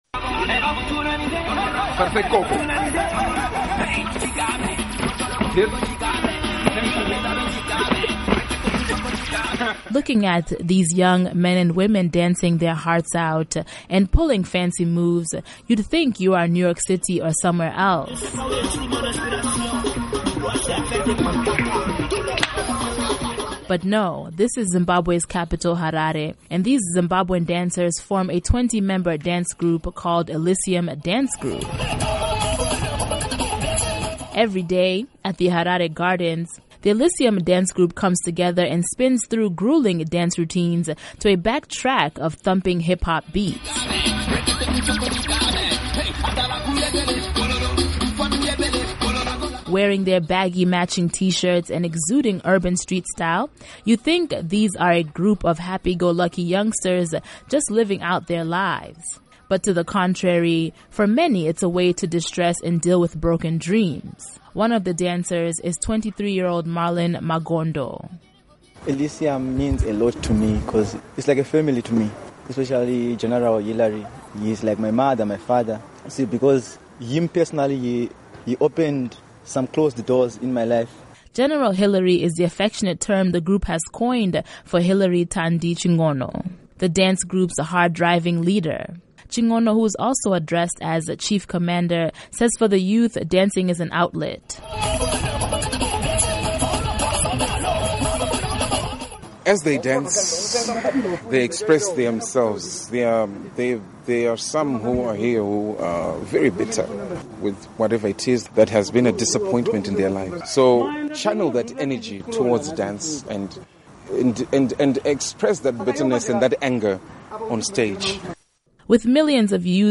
Report On Zimbabwe Hip-Hop Dancers: The Elysium Dance Group